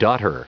Prononciation du mot daughter en anglais (fichier audio)
Prononciation du mot : daughter